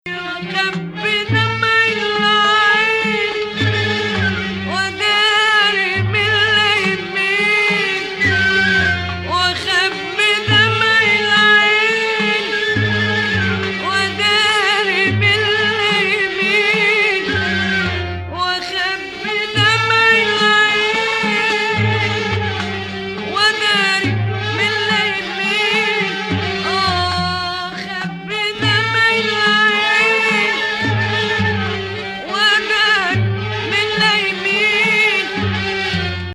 Maqam Rast
final phrase ambiguous, leading to Saba
strong "tone painting" here--"I cover my tears" etc.